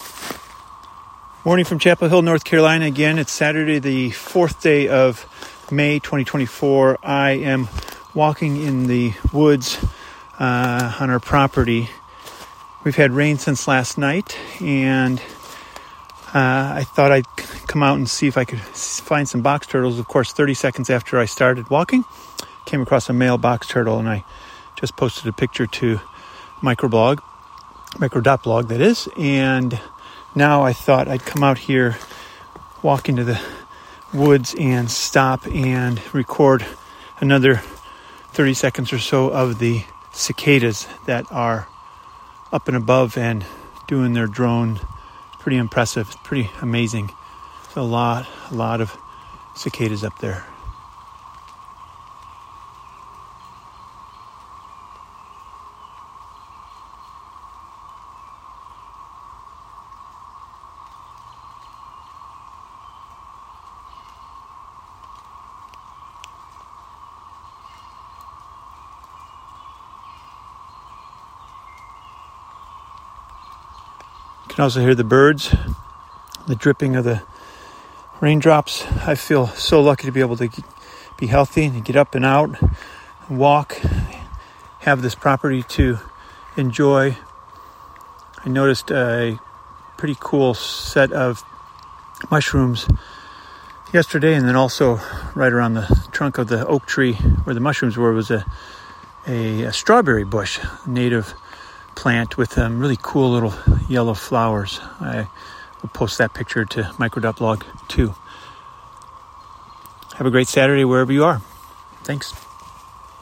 Raindrops and Turtles
Another walk in the woods to find box turtles and listen to the birds and cicadas.